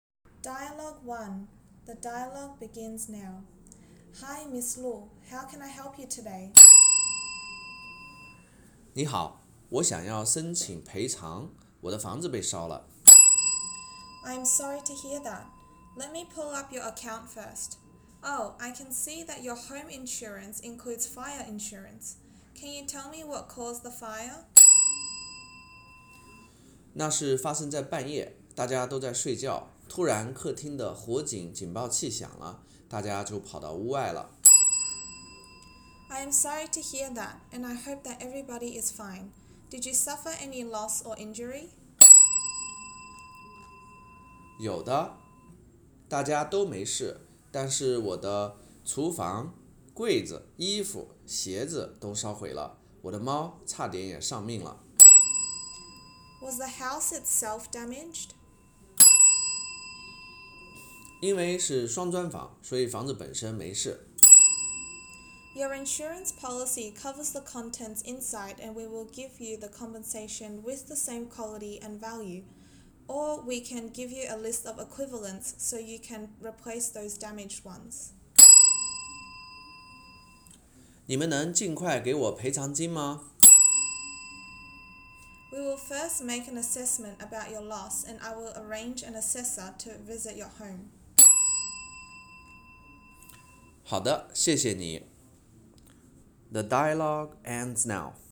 墨尔本文波英语培训学校给CCL考生录了最近2019年的CCL真题，希望对大家备考有用。
这是一篇关于Lu女士和房子保险中心的jack的对话